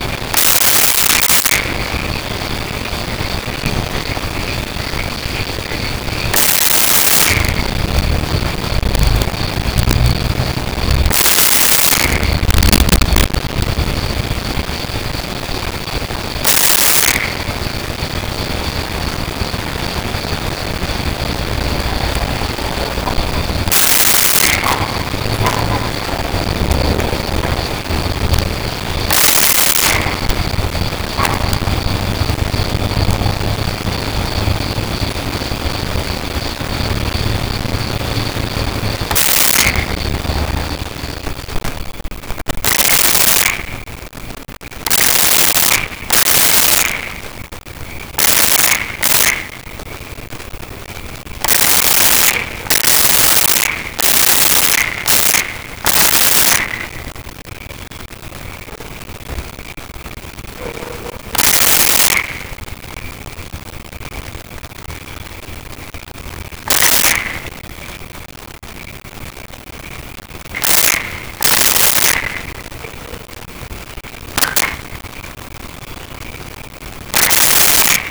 Frog Croaks
Frog Croaks.wav